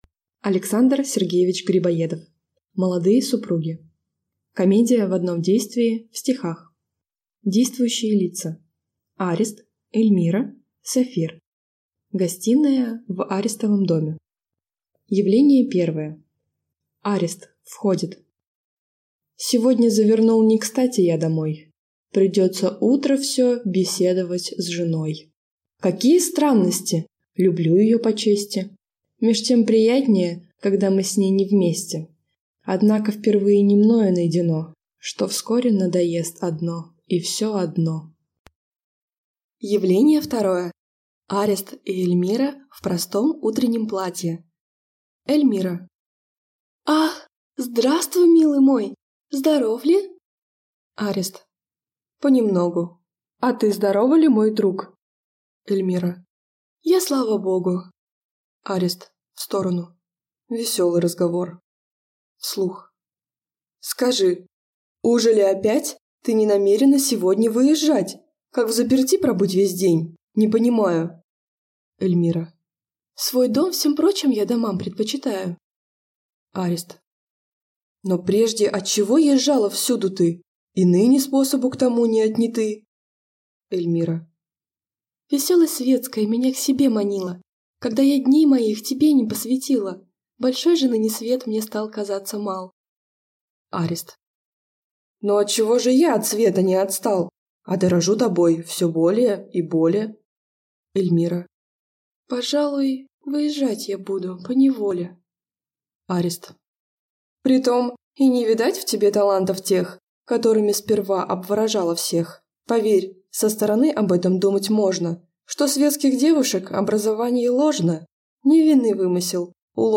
Аудиокнига Молодые супруги | Библиотека аудиокниг